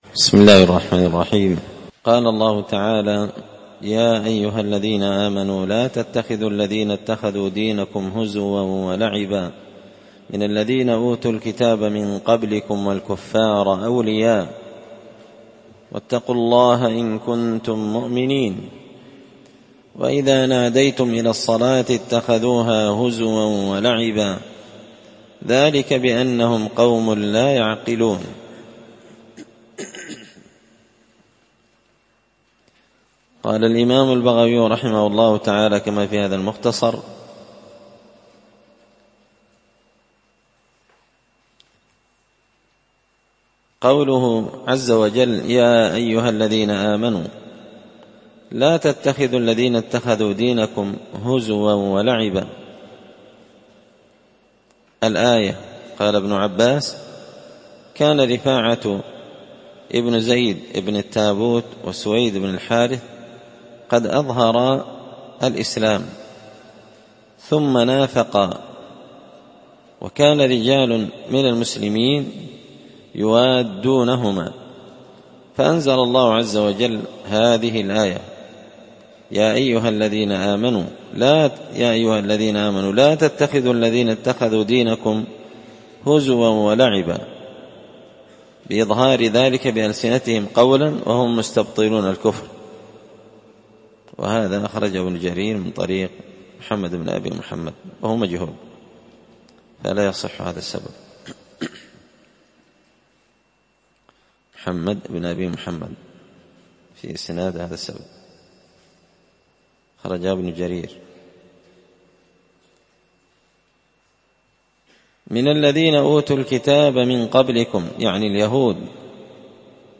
مختصر تفسير الإمام البغوي رحمه الله ـ الدرس 264 (سورة المائدة الدرس 37)
مسجد الفرقان